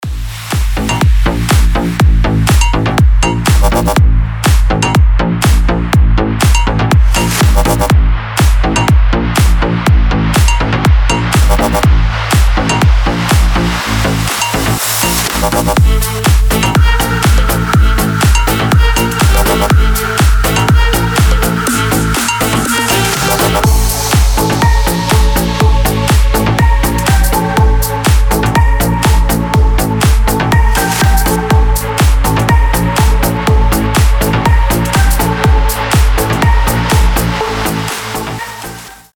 • Качество: 320, Stereo
ритмичные
Club House
без слов
future house
энергичные
Bass
G-House
динамичные
Стиль: G-house, club house